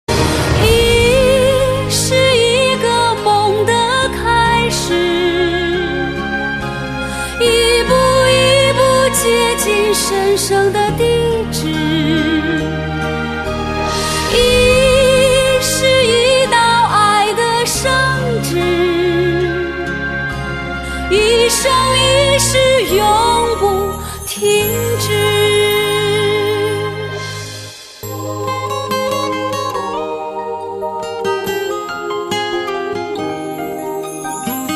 M4R铃声, MP3铃声, 华语歌曲 143 首发日期：2018-05-15 17:50 星期二